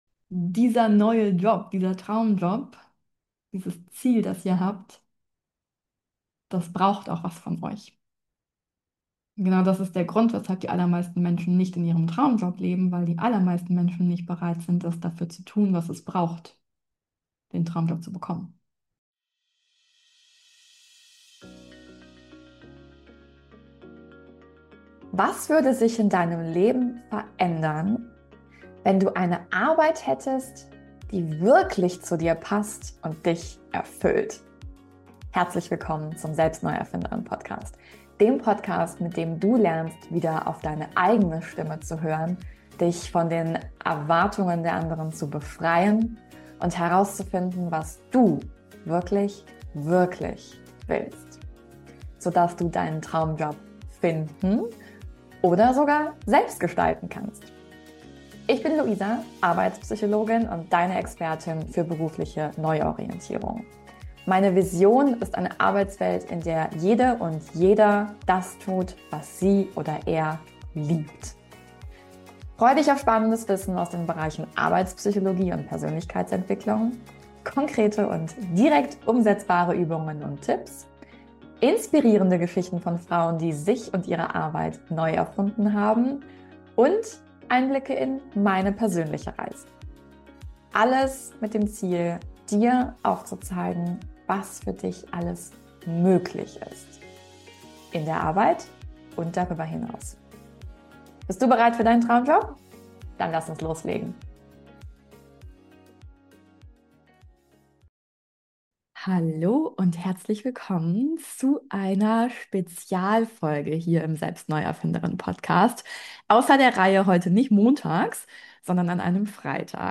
Heute gibt's eine Spezialfolge außer der Reihe - und zwar bekommst Du hier ganz exklusiv einen Einblick in den live Workshop MISSION TRAUMJOB.
Gestern (am 19. Februar) fand der erste Teil des Live Workshops statt und in dieser Folge kannst Du Dir schon mal einen Teil der Aufzeichnung anhören.